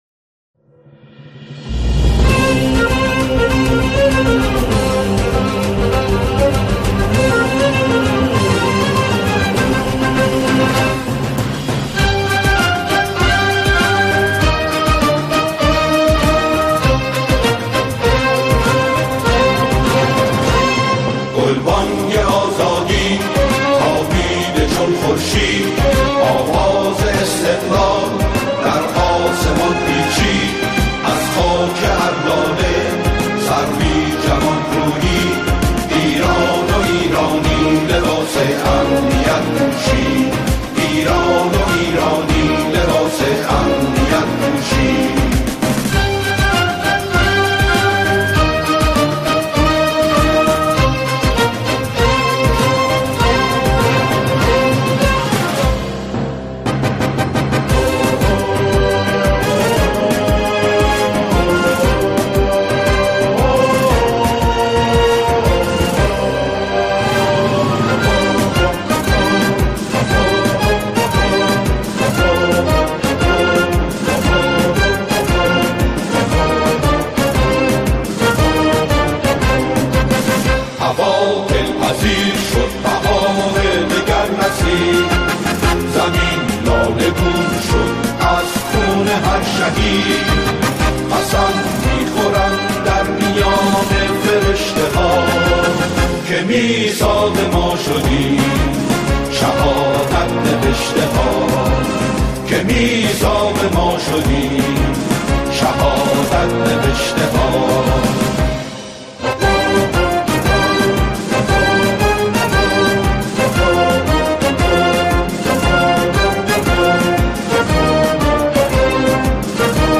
گروهی از جمعخوانان